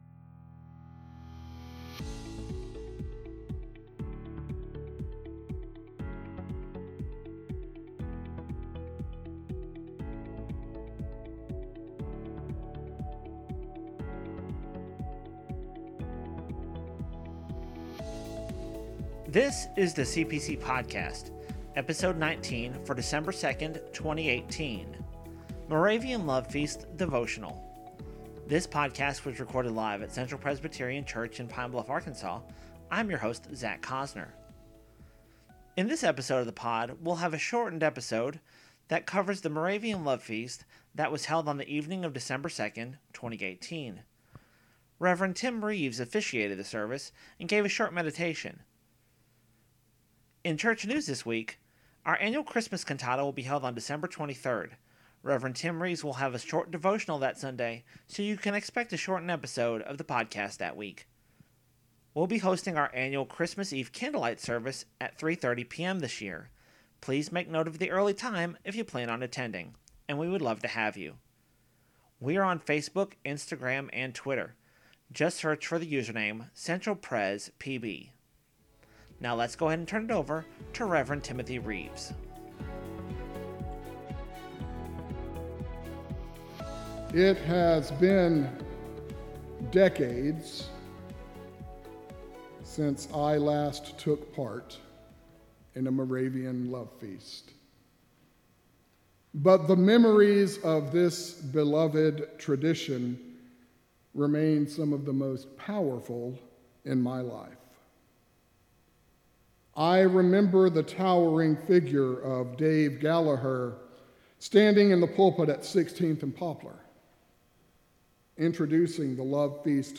cpcpb-episode-19-love-feast-devotional.mp3